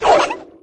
girl_toss_plane.wav